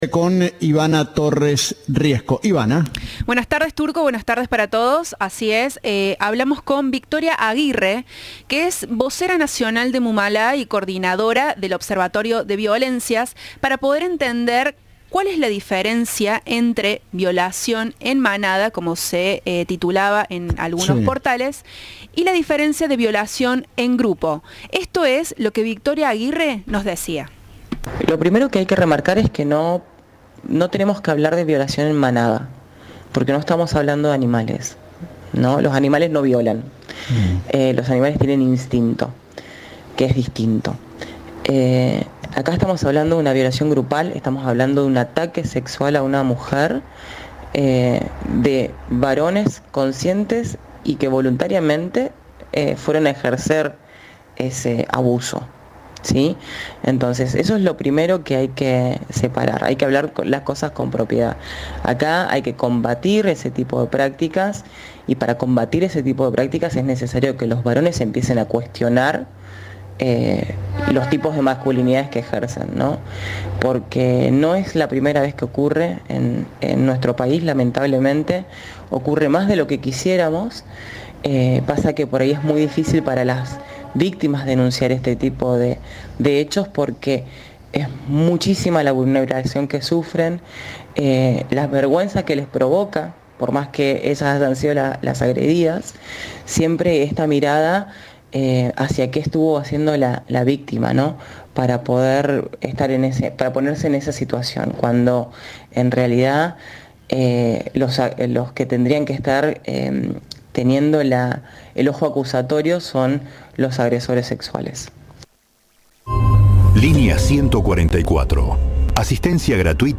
Informe de Resumen 3.